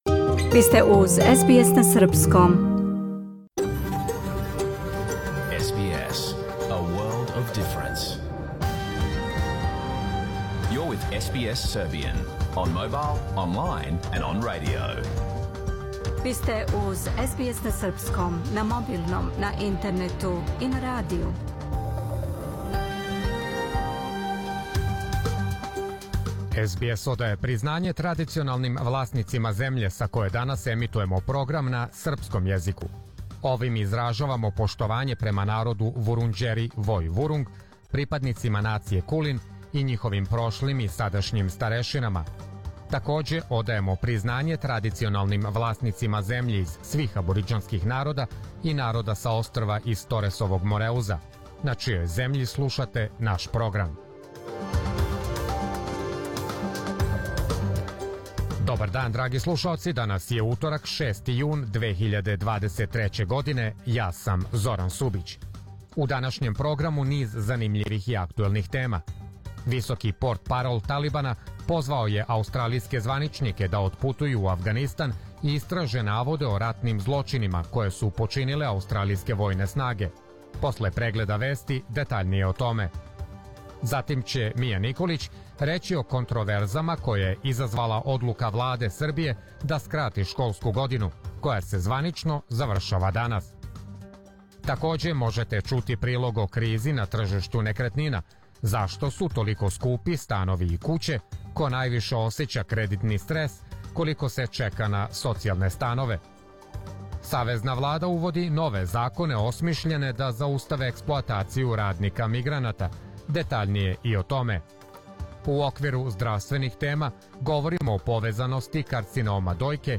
Програм емитован уживо 6. јуна 2023. године
Ако сте пропустили данашњу емисију, можете да је слушате у целини као подкаст, без реклама.